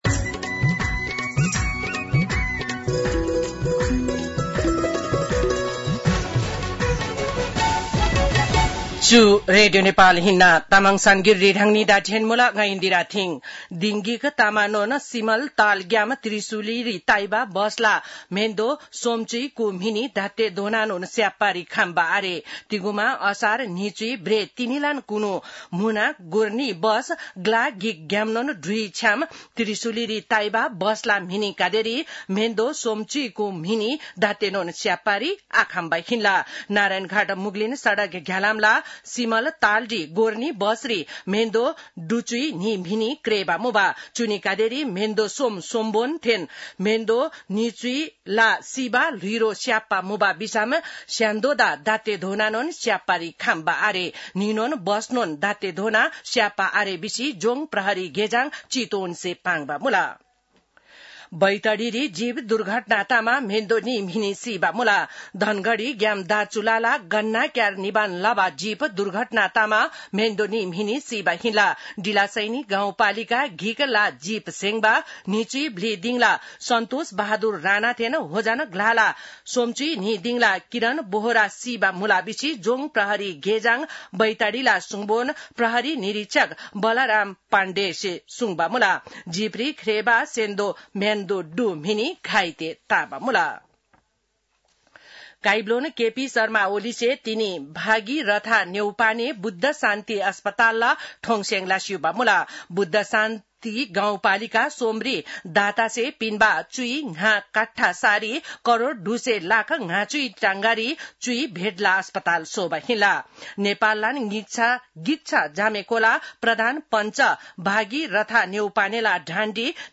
तामाङ भाषाको समाचार : २८ असार , २०८२